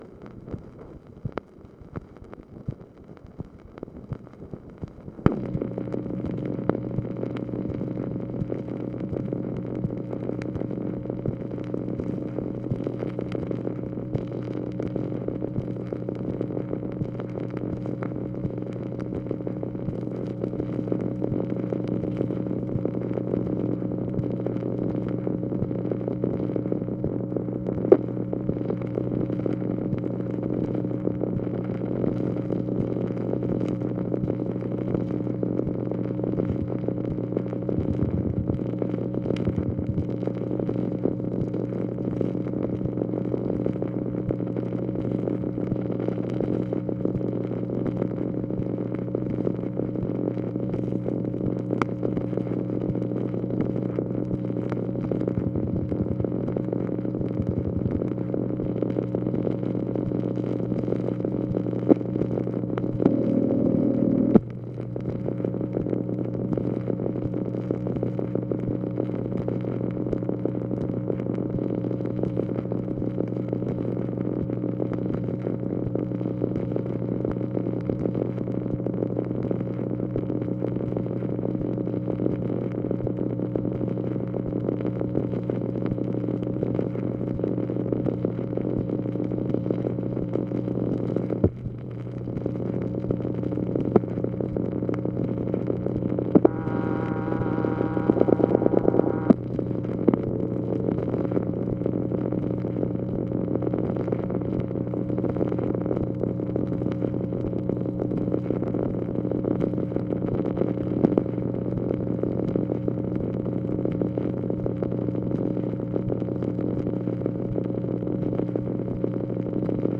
MACHINE NOISE, February 12, 1965
Secret White House Tapes | Lyndon B. Johnson Presidency